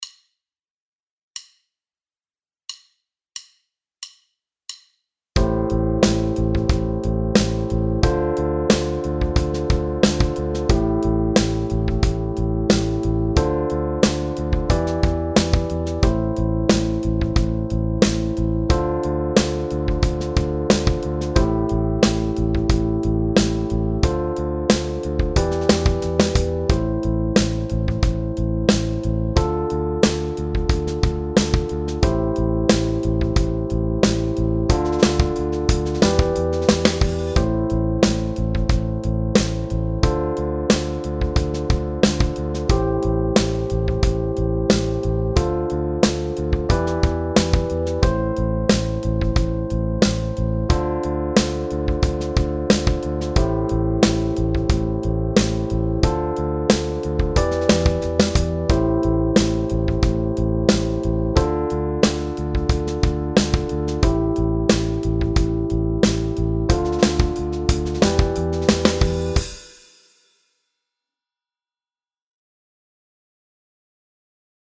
backing tracks penta mineure